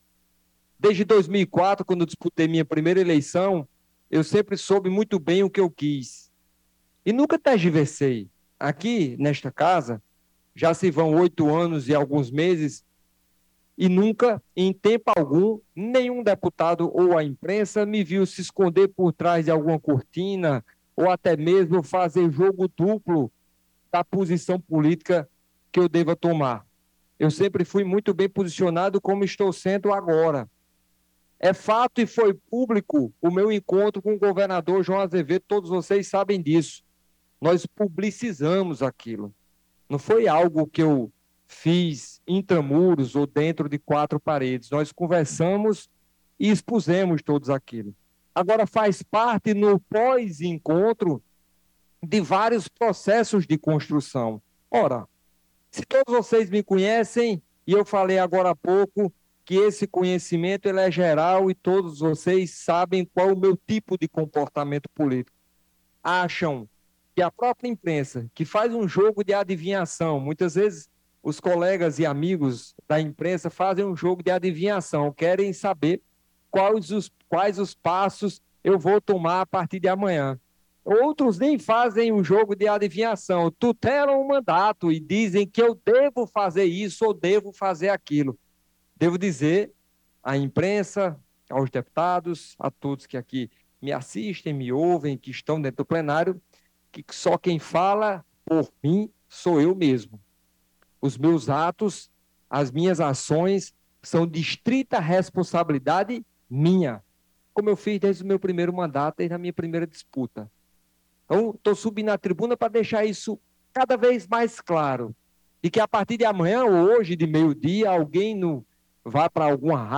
Em meio a rumores e discussões sobre sua possível adesão à base governista, o deputado estadual de oposição Tovar Correia Lima (PSDB) fez uma declaração enfática em resposta às especulações que têm circulado nos corredores políticos e na mídia. O parlamentar reafirmou sua autonomia e sua história política durante um pronunciamento no plenário.